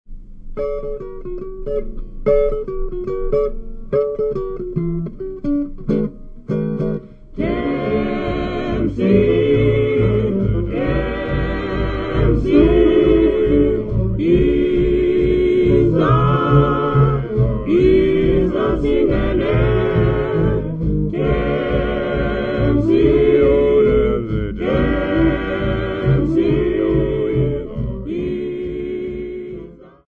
Cape inkspots of Grahamstown
Folk music--Africa
Field recordings
sound recording-musical
A topical song about a girl named Themb'sie accompanied by saxophone, double bass, drum and guitar.